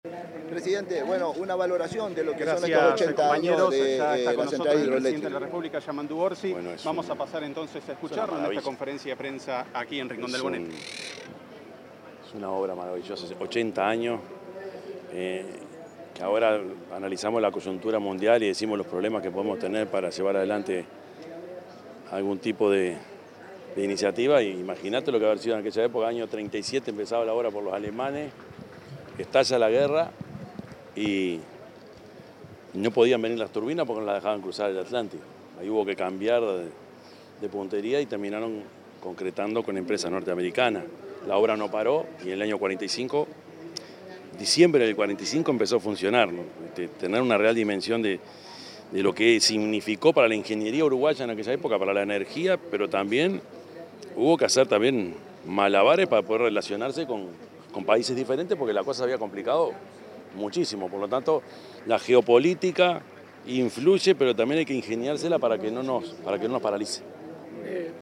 Declaraciones del presidente de la República, Yamandú Orsi
En el 80.° aniversario de la central hidroeléctrica Rincón del Bonete, ubicada en Paso de los Toros, Tacuarembó, realizó declaraciones el presidente